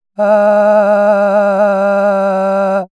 Physics-based simulation of disordered vocal timbres generated by SimuVox for clinical training and perceptual research.
Tremor:
• Tremor: Simulated through low-frequency modulation of subglottal pressure and laryngeal muscle tension
female_tremor.wav